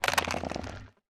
creaking_idle5.ogg